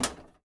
breaker_open.ogg